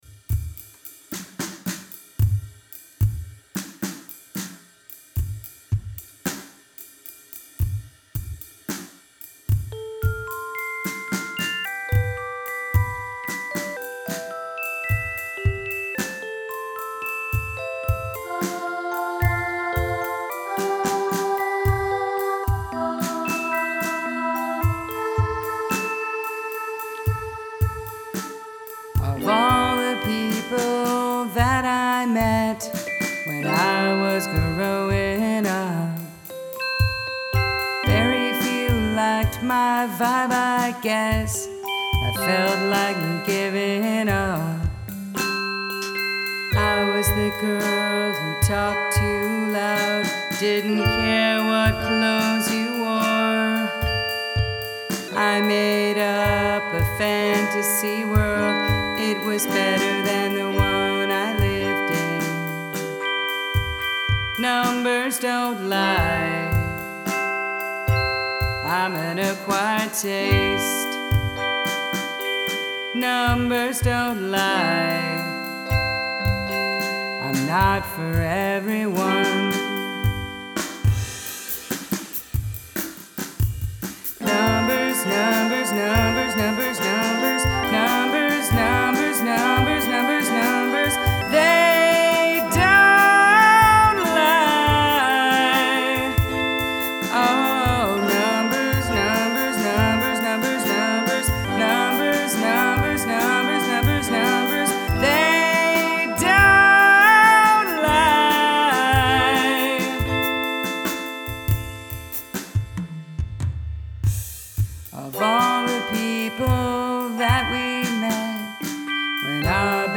Use the Royal Road chord progression
NOTES: Royal Road progression is in the chorus (F G Em Am)
I like the purposefully atonal type vibes in there.
Twinkly instrumentation.
Vocals are stronger here than last couple entries though.